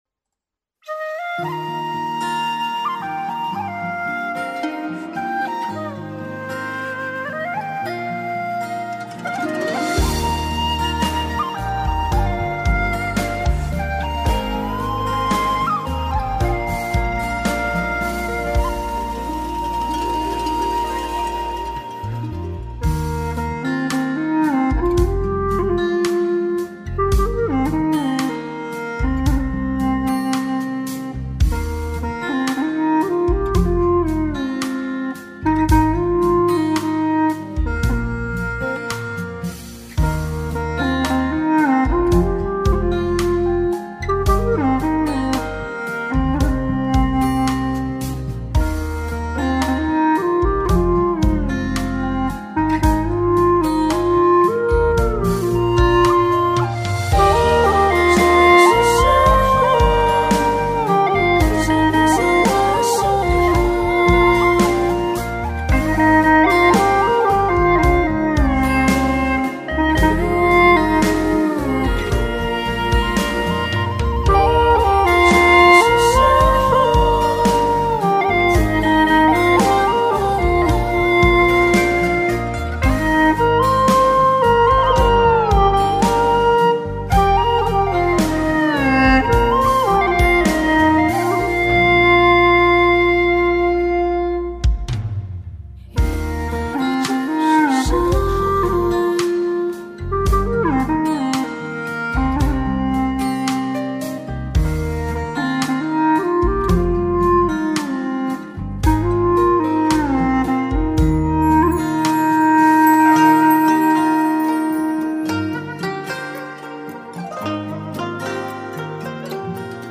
调式 : 降E 曲类 : 流行
【大小降E调】